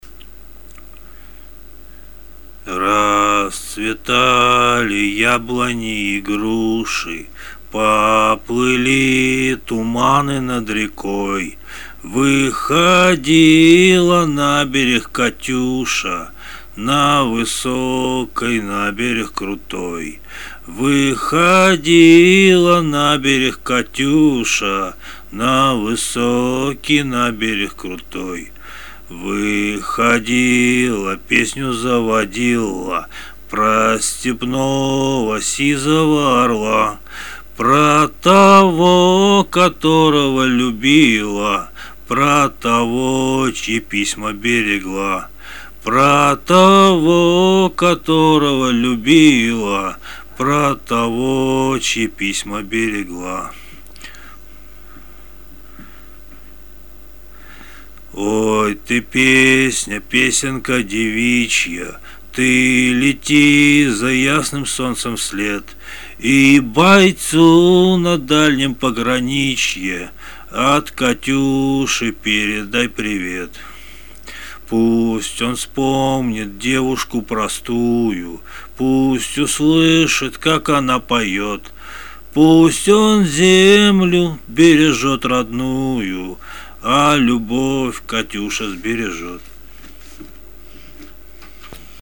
песню спел